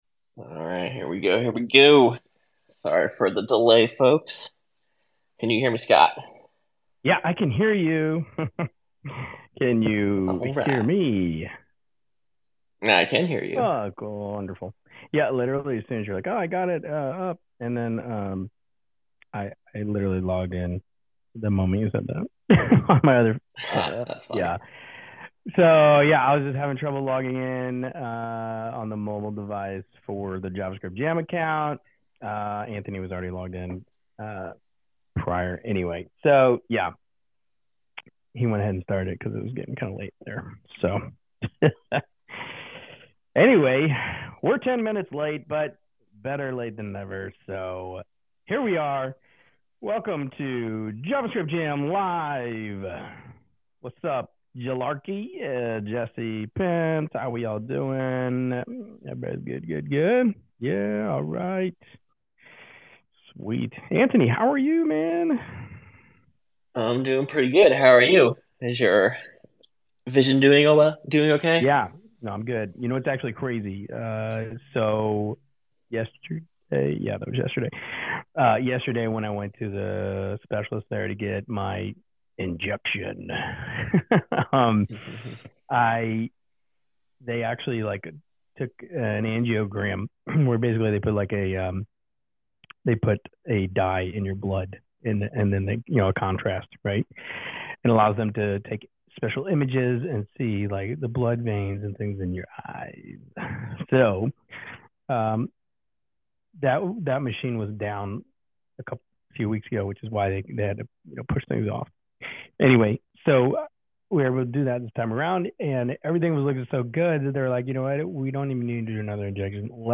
A panel discusses the latest in JavaScript development, covering topics from AI-assisted WordPress plugins to React server components